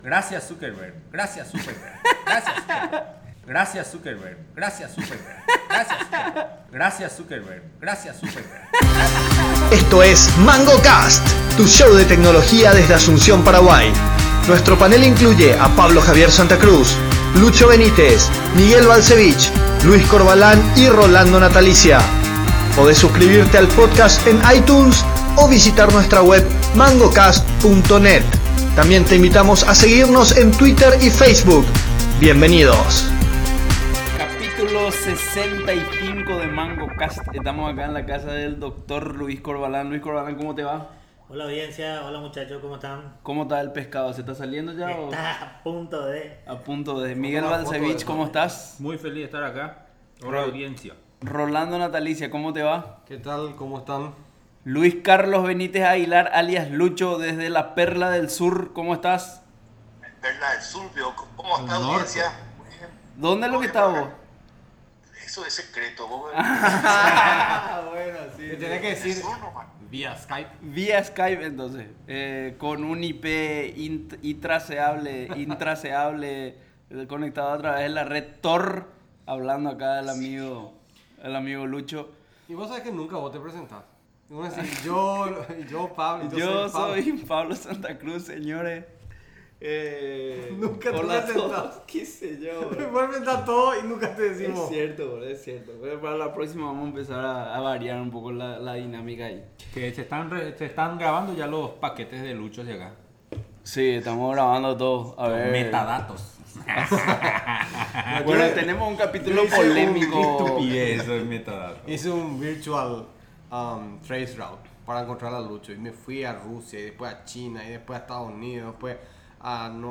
Entrevista Argentina en Python - PyDay Asunción